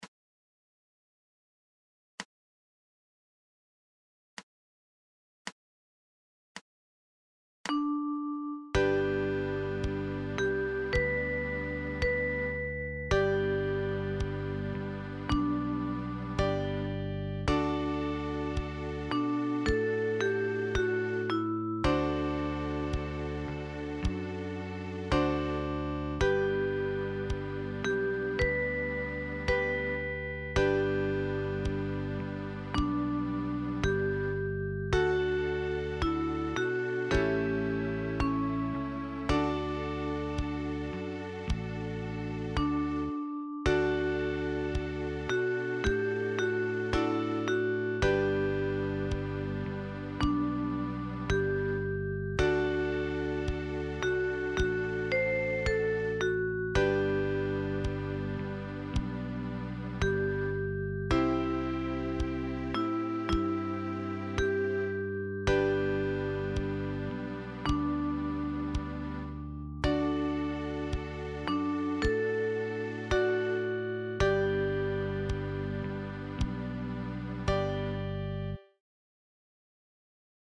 Sound samples – melody & band and band alone: